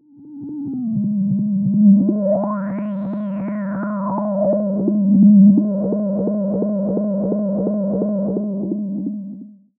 Filtered Feedback 16.wav